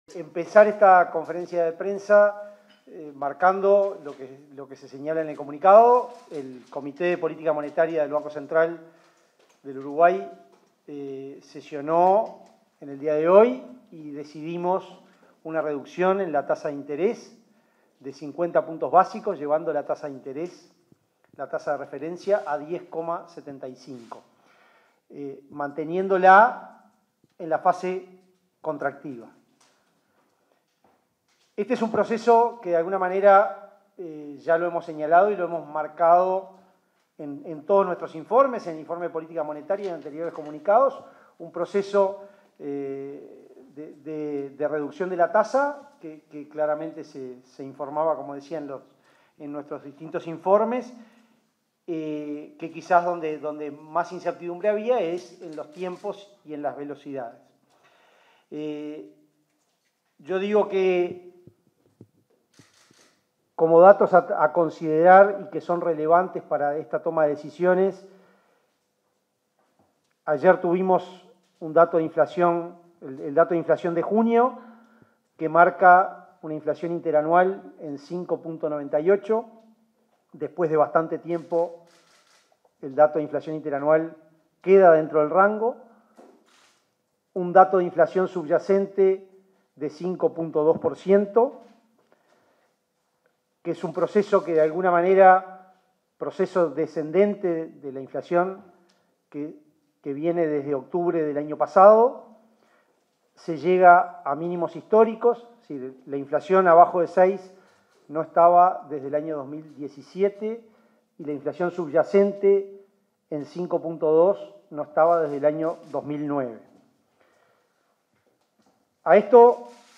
Palabras del presidente del Banco Central del Uruguay, Diego Labat
En el marco de la reunión del Comité de Política Monetaria, este 6 de julio, se expresó el presidente del Banco Central del Uruguay, Diego Labat.